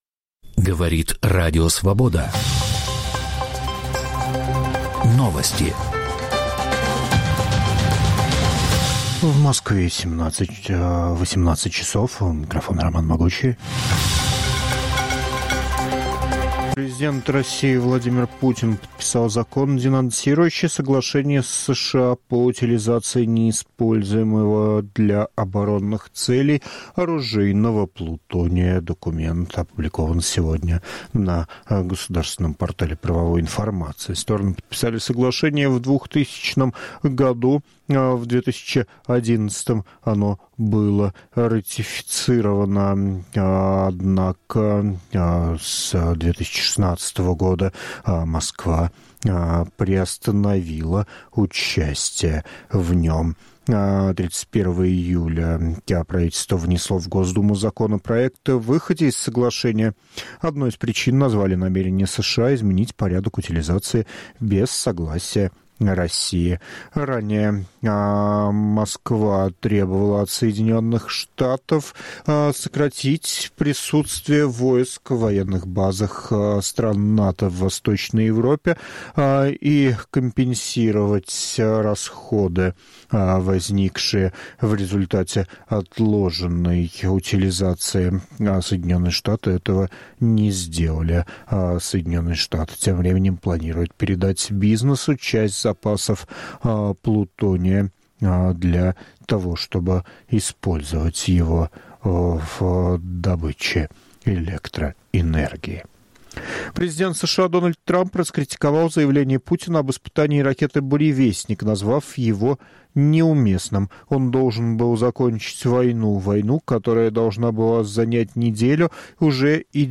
Аудионовости